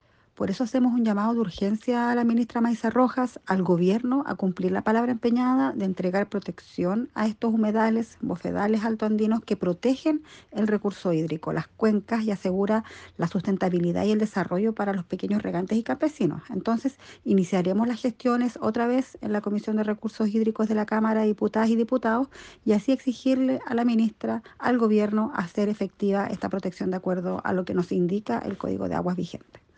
La diputada Nathalie Castillo, en tanto, planteó que